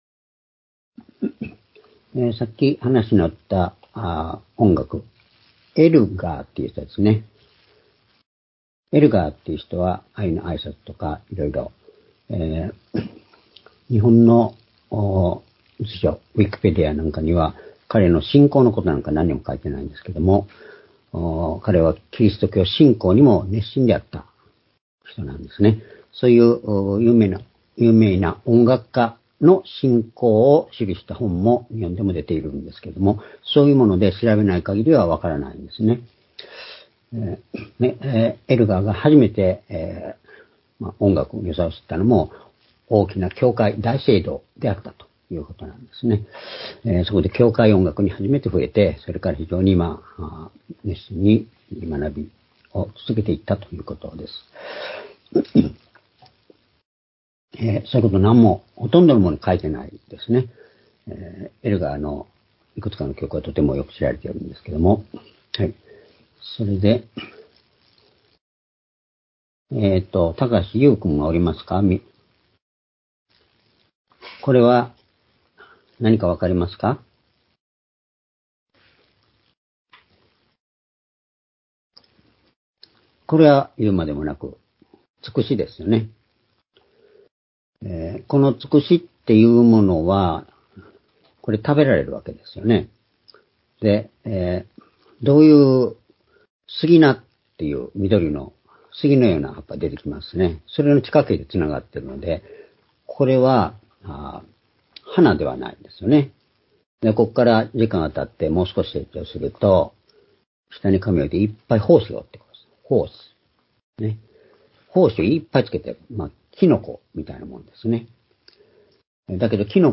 主日礼拝日時 2023年3月26日(主日) 聖書講話箇所 「神とキリストを知る」 ヨハネ8章52～55節 ※視聴できない場合は をクリックしてください。